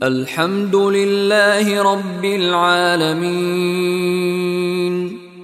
Contoh Bacaan dari Sheikh Mishary Rashid Al-Afasy
Bunyi huruf Lam disebut dengan JELAS tanpa dengung